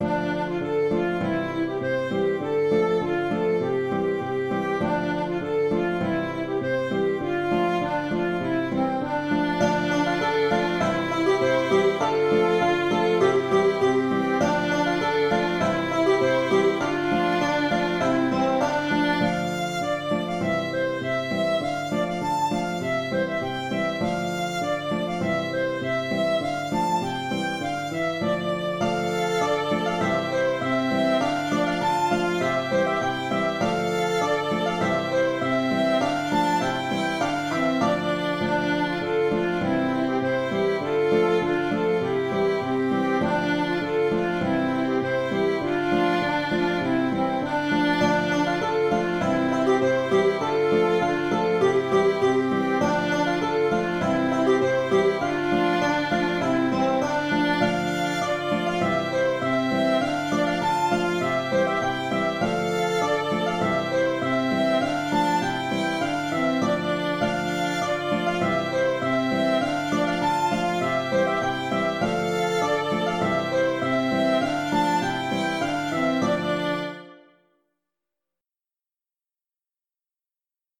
Polka en ré mineur facile à apprendre, que j'ai trouvée dans un fascicule d'apprentissage de l'accordéon diatonique de Yann DOUR. Tout se joue sur la rangée intérieure, même le contrechant, très simple. Il est important de ne pas ralentir le tempo, voire à l'accélérer peu à peu.